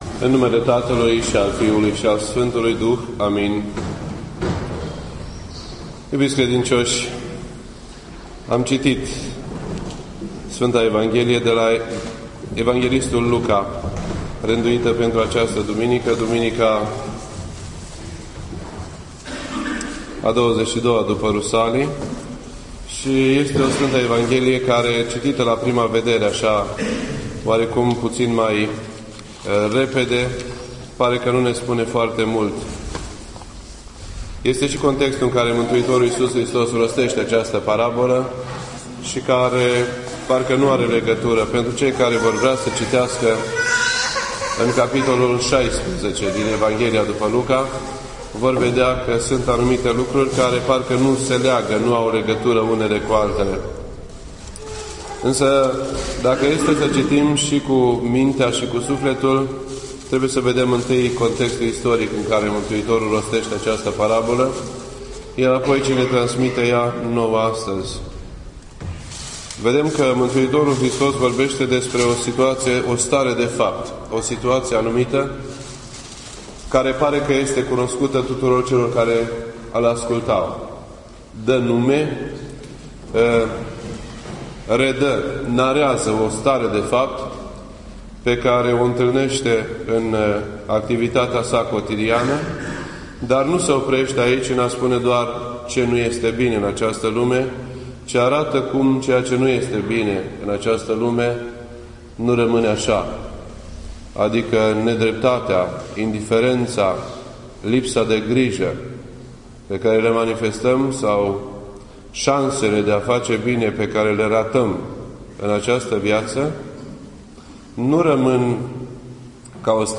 This entry was posted on Sunday, November 4th, 2012 at 8:20 PM and is filed under Predici ortodoxe in format audio.